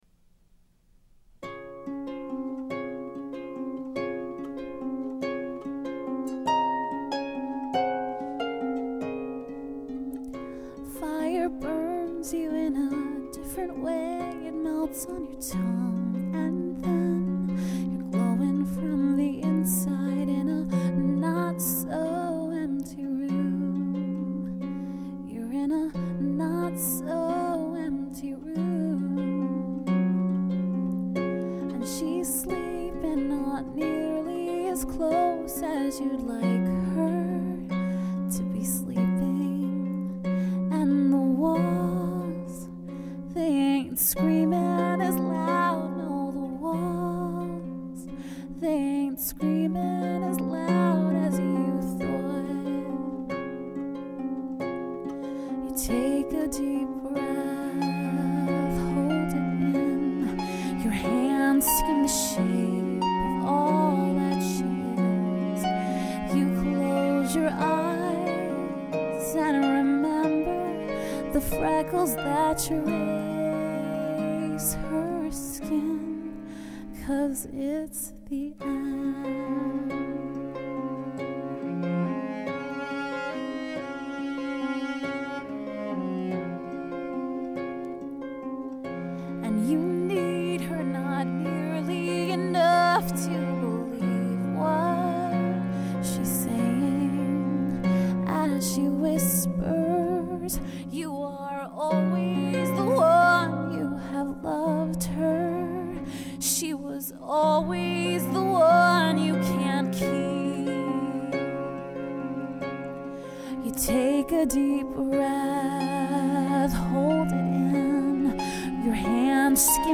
Original song
vocalist
cellist